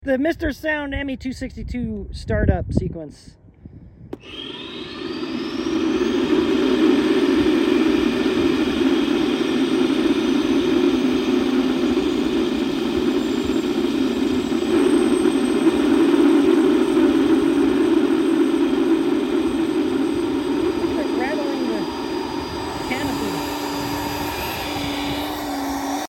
It has multiple aircraft on sound effects free download
It has multiple aircraft on it.